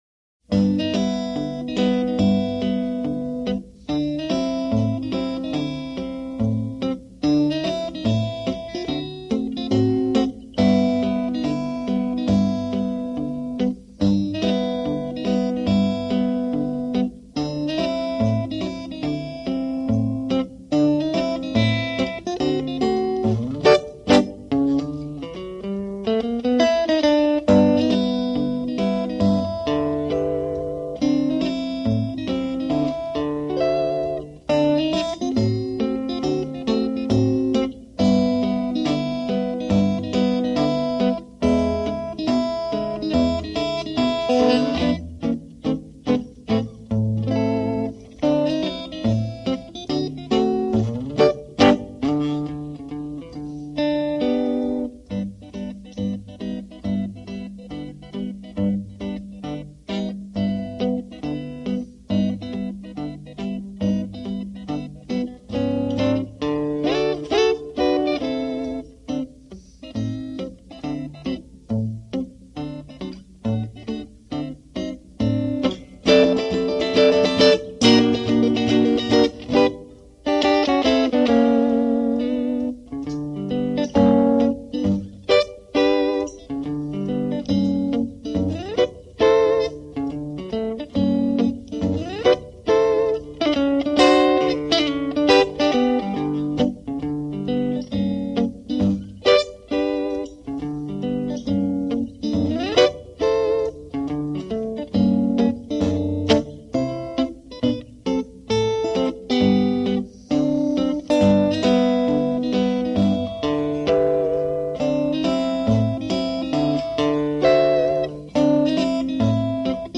【爵士吉他】
弹奏风格，使用拇指击打低音弦，他使这种弹奏法成为了乡村吉他演奏的标准。